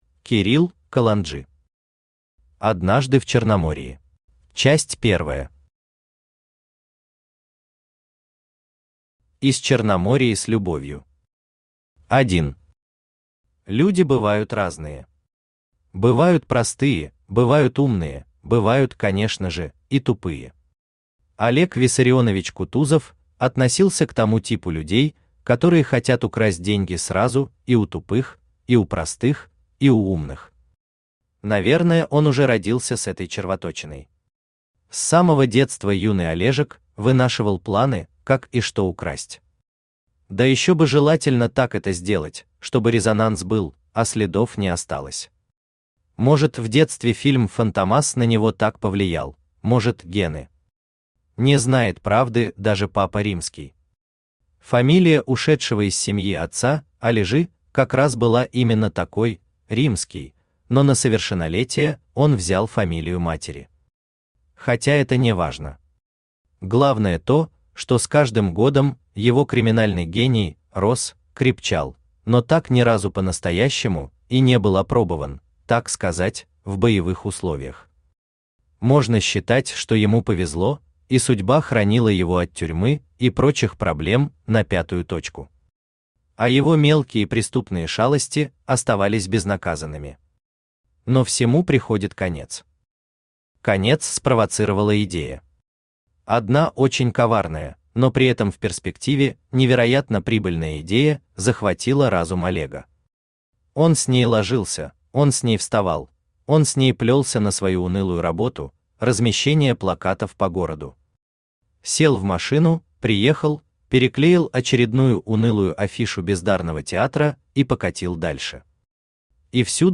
Aудиокнига Однажды в Черномории Автор Кирилл Александрович Каланджи Читает аудиокнигу Авточтец ЛитРес.